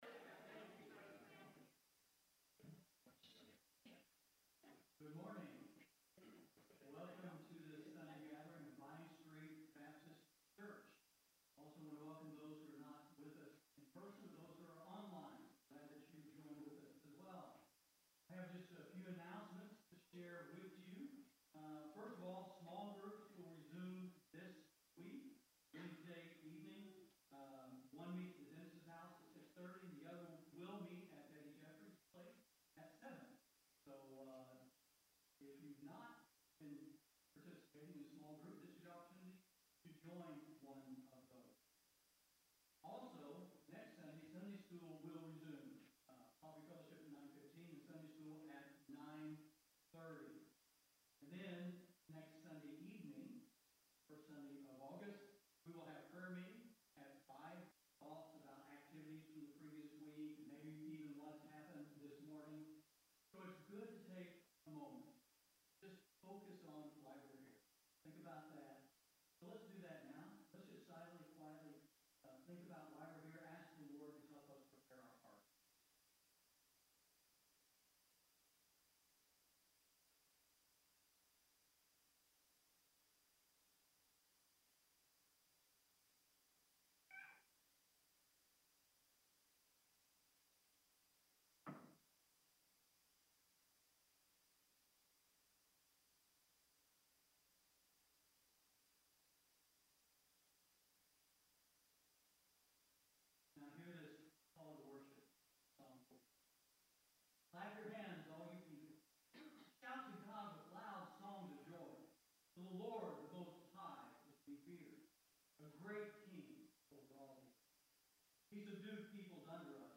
Due to a technical recording issue, the audio for this sermon is not available.
There are also sections of working audio in the file below: July 30 Worship Audio – Full Service